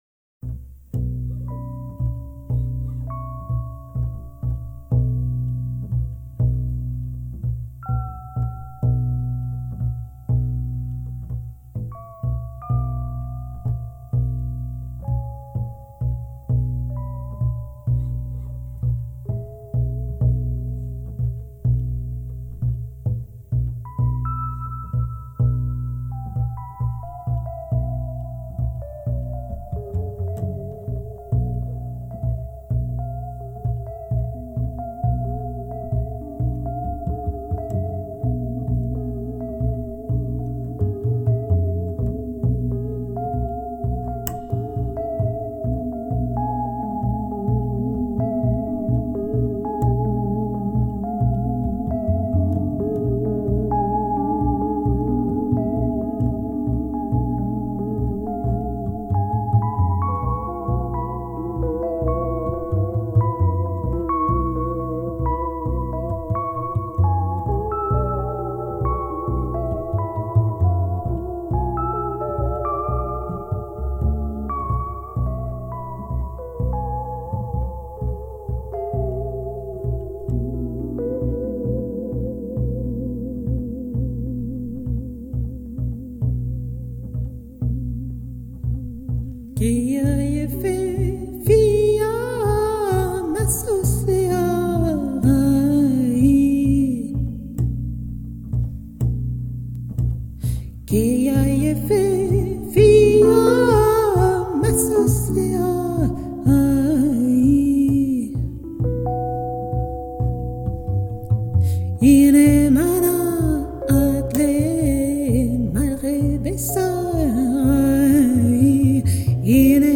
mêlant compositions, improvisation, et chants du monde.
Arabesques poétiques aux silences rêveurs.
Afin de percevoir, l'espace, les nuances, les silences...